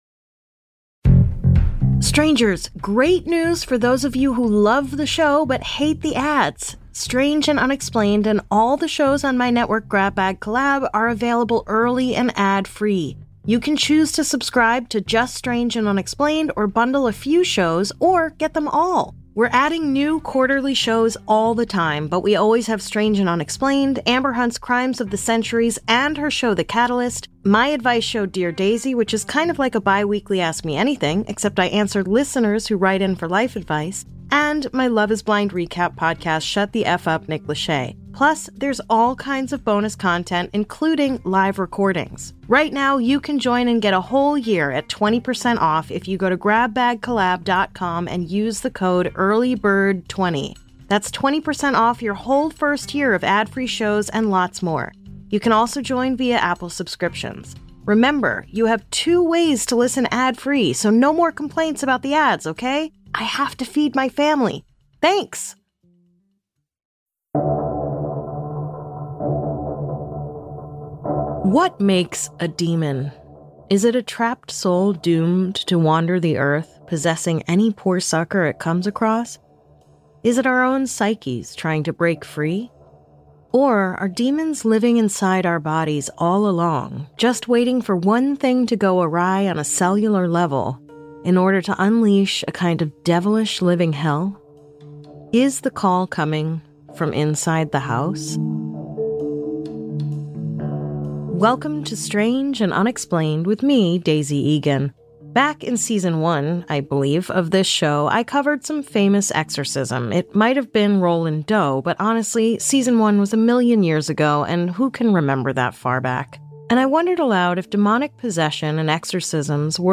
Written and hosted by Tony Award-winning actor and celebrated writer Daisy Eagan, “Strange and Unexplained” is a journey into the uncomfortable and the unknowable that will leave you both laughing and sleeping with the lights on.